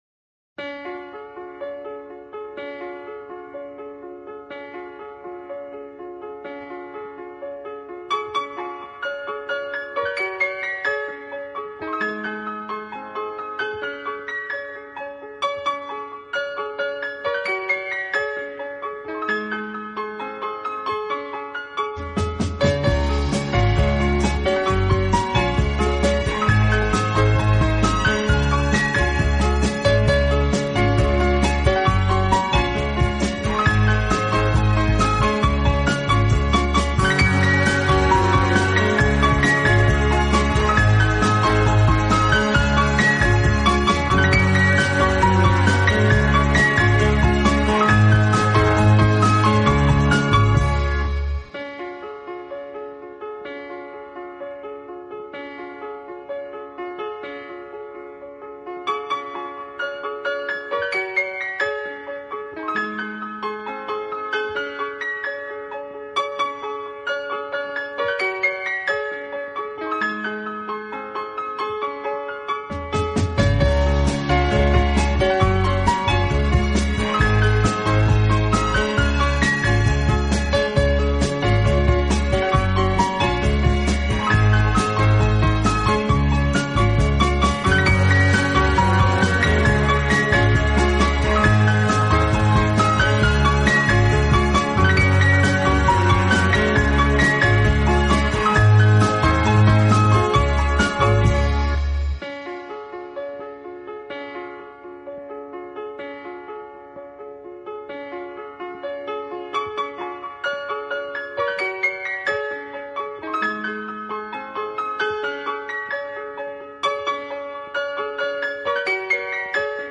Genre: Instrumental